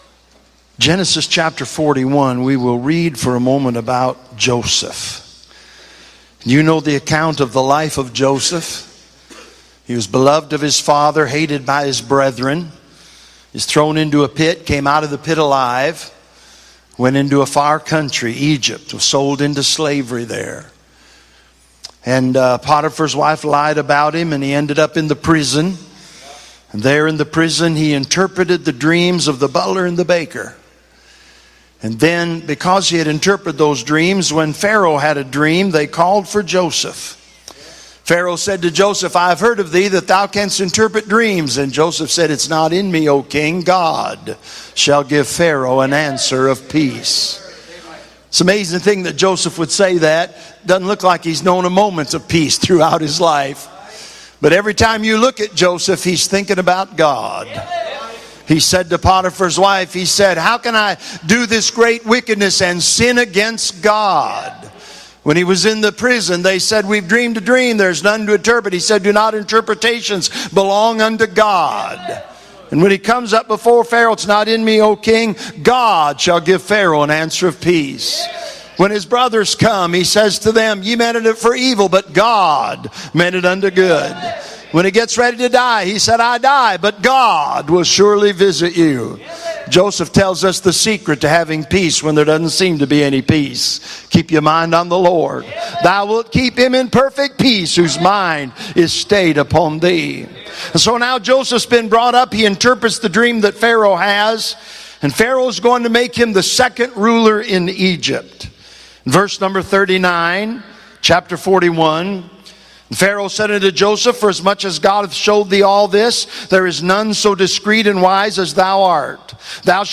A sermon preached Monday Evening during our Spring Jubilee, on March 25, 2024.